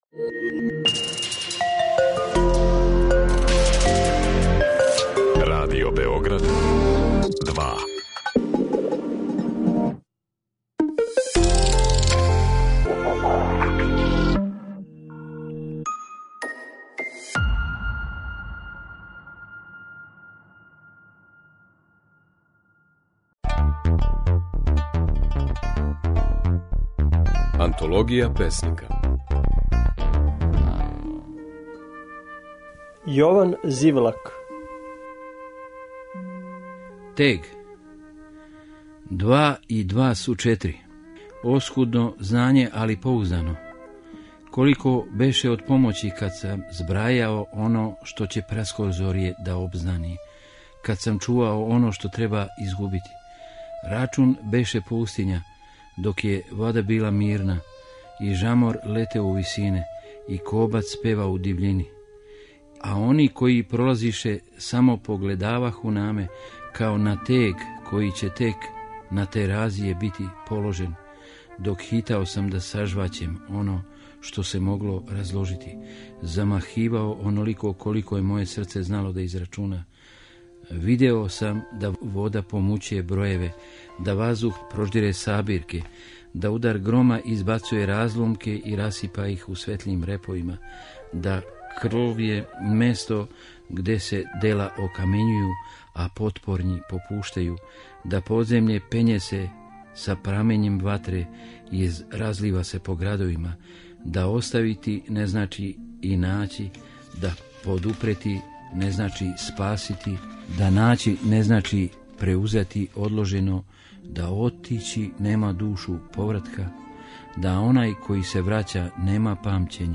Можете чути како своје стихове говори песник Јован Зивлак
Емитујемо снимке на којима своје стихове говоре наши познати песници